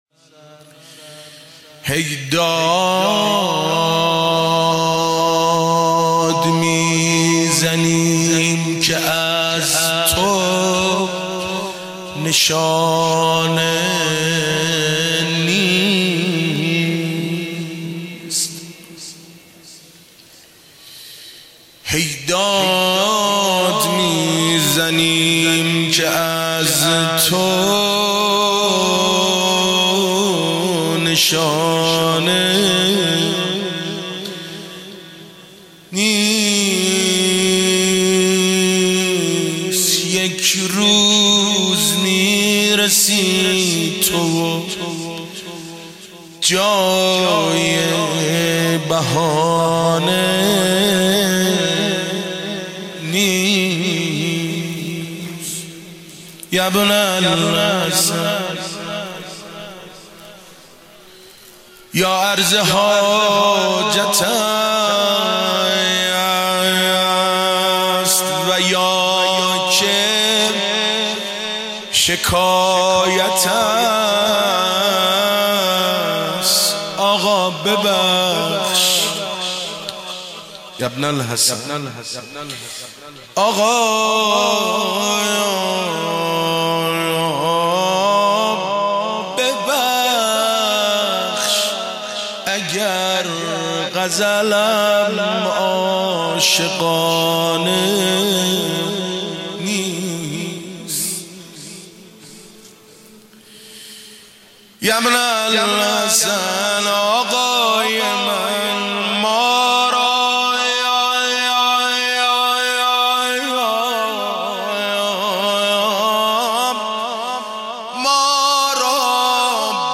پانزدهمین اجتماع مدافعان حرم در مهدیه تهران